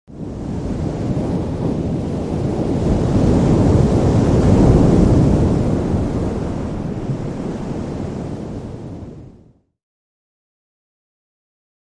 دانلود آهنگ وال 14 از افکت صوتی انسان و موجودات زنده
جلوه های صوتی
برچسب: دانلود آهنگ های افکت صوتی انسان و موجودات زنده دانلود آلبوم صدای حیوانات آبی از افکت صوتی انسان و موجودات زنده